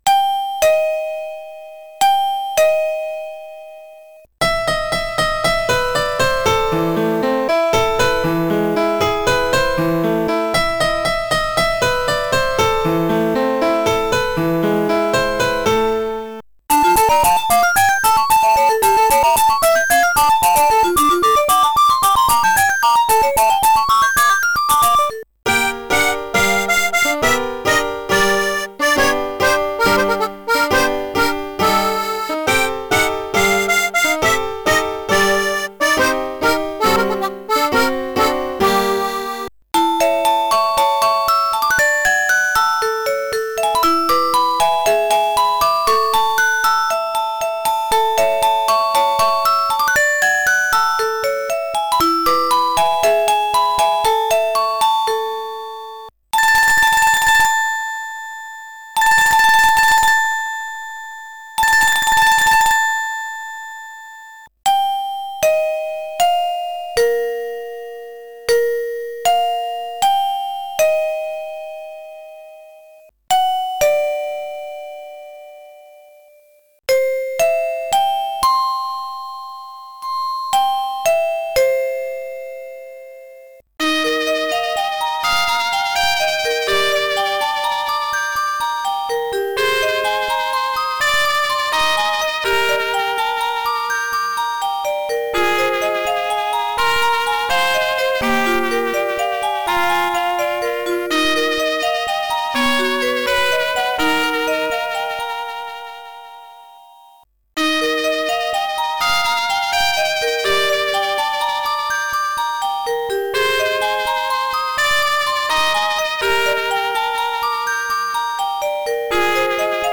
• Choix de 58 sonneries premium et de 4 variantes d'éclairage LED
Melodien_169614.mp3